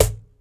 Hand Cajon 02.wav